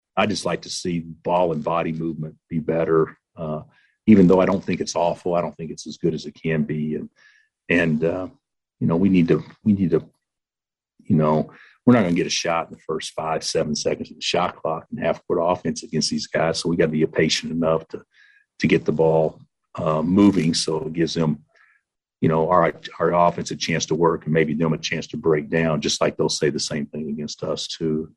Kansas Coach Bill Self says he would like to see improvement offensively.